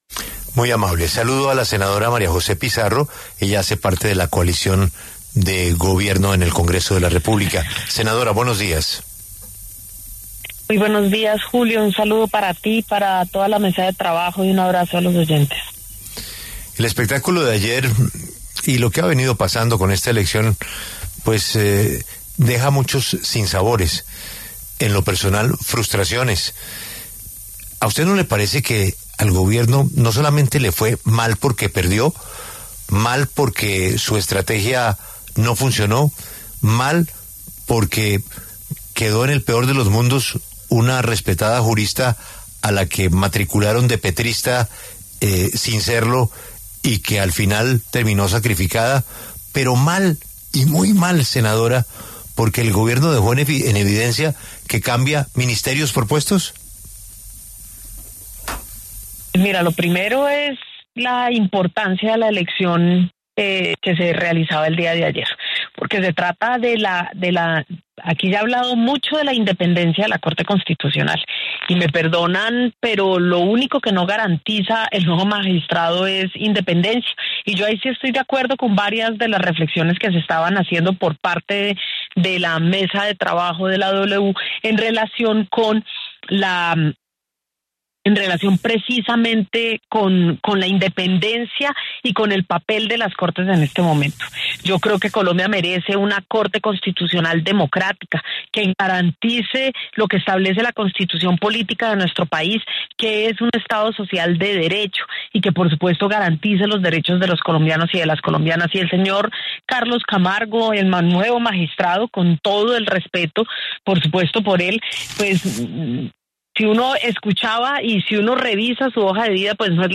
María José Pizarro, senadora del Pacto Histórico, conversó con La W sobre la elección de Camargo como magistrado y la derrota de María Patricia Balanta.